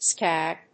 /skˈæg(米国英語)/